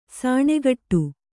♪ sāṇegaṭṭu